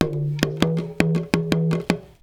PERC 22.AI.wav